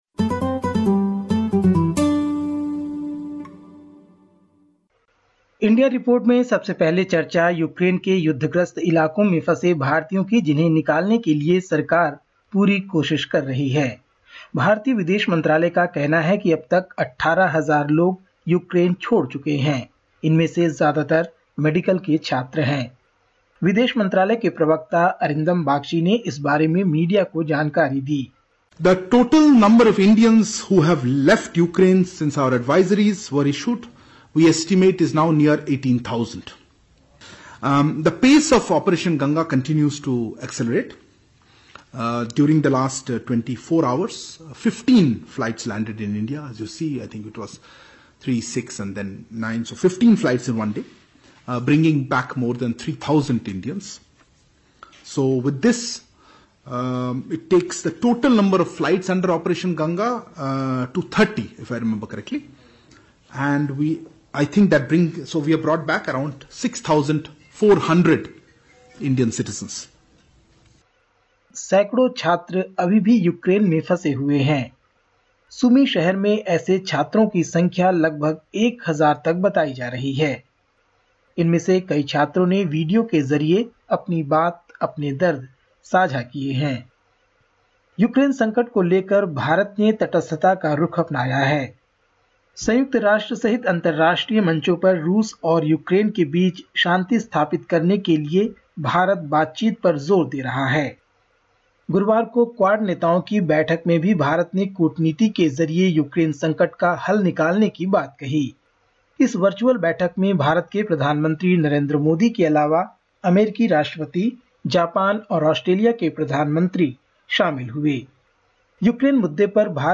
Listen to the latest SBS Hindi report from India. 04/03/22